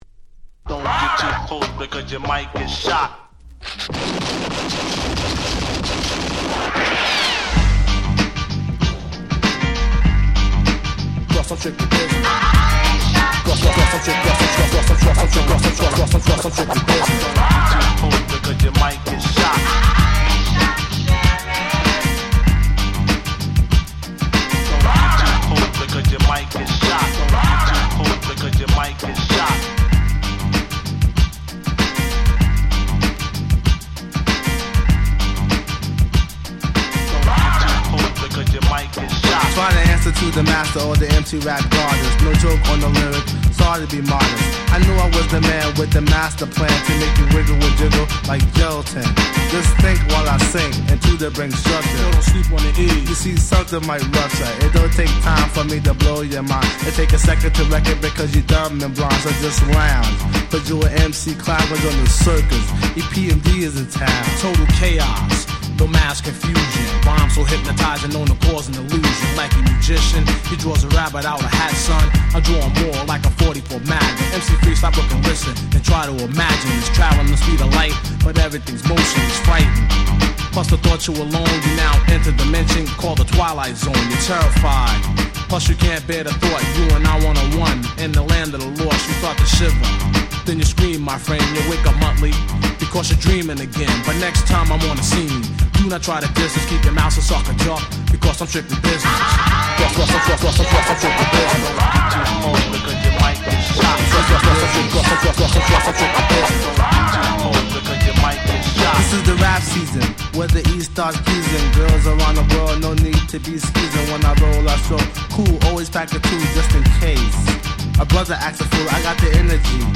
【Media】Vinyl 12'' Single
88' Super Hip Hop Classics !!
90's Boom Bap オールドスクール ミドルスクール Old School Middle